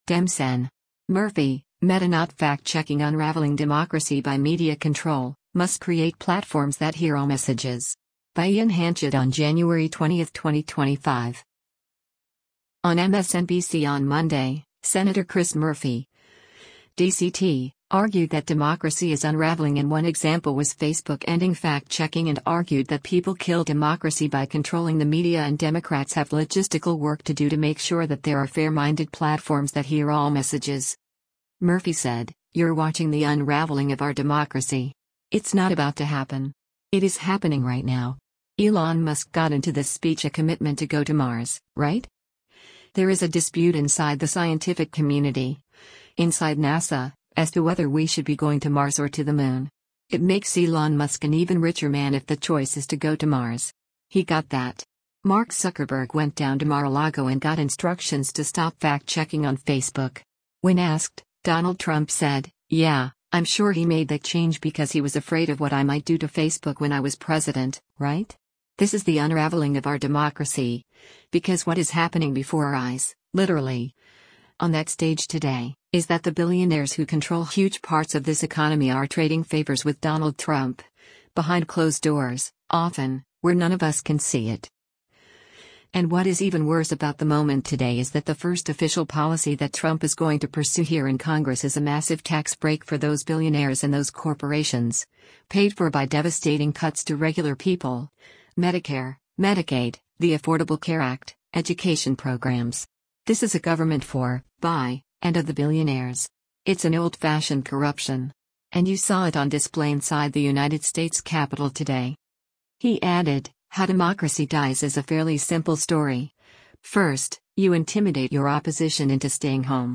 On MSNBC on Monday, Sen. Chris Murphy (D-CT) argued that democracy is unraveling and one example was Facebook ending fact-checking and argued that people kill democracy by controlling the media and Democrats have “logistical work to do to make sure that there are fair-minded platforms that hear all messages.”